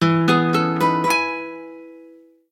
01_Acoustic_Guitar.ogg